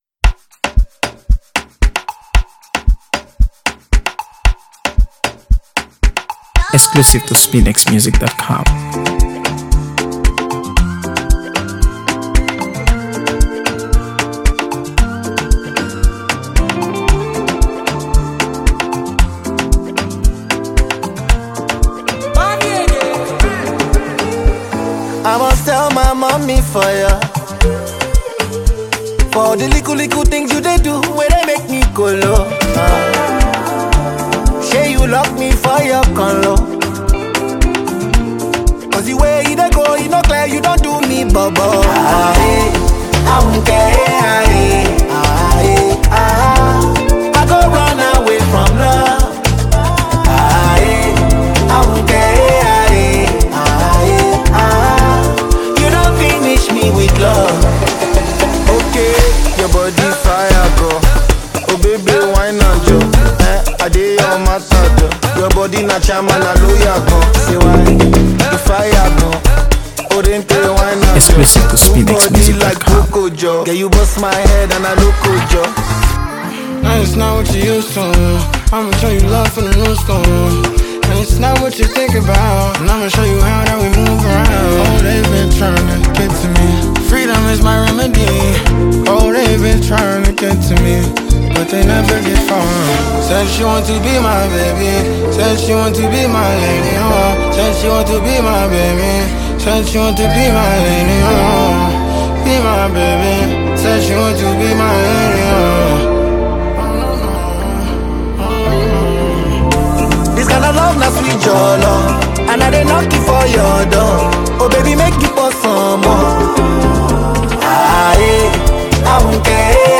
AfroBeats | AfroBeats songs
the perfect blend of contagious rhythm and style